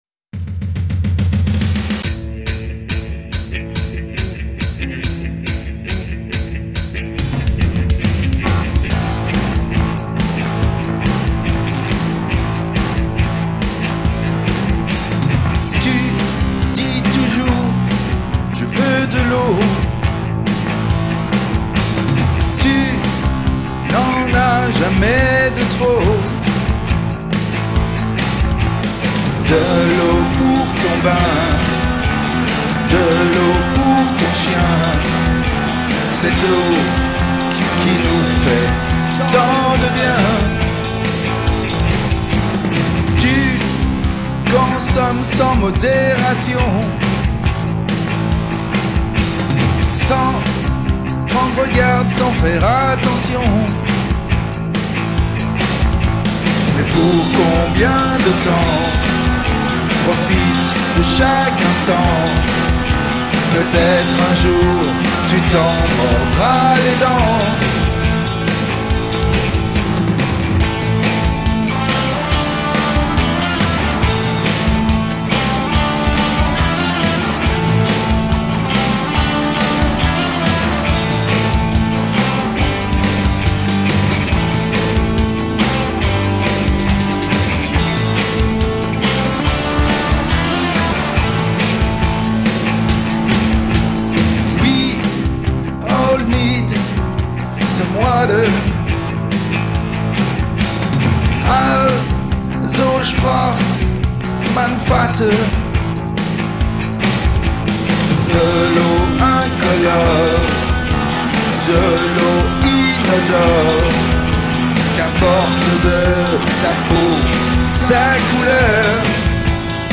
voix, claviers, guitares,basse et programmation batterie
La Fiction pop-rock